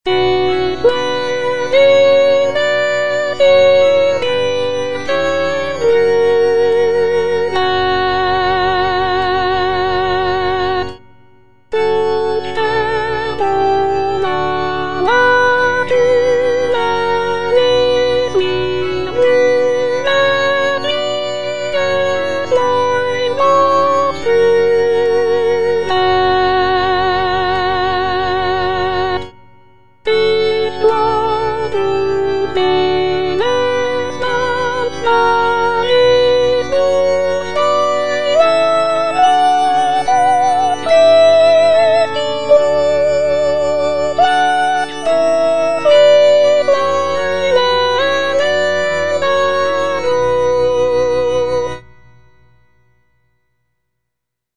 The work features a joyful and optimistic tone, with the solo soprano expressing gratitude for the blessings in her life. The text explores themes of contentment, trust in God, and the acceptance of one's fate.